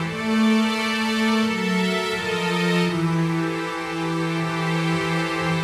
AM_80sOrch_85-A.wav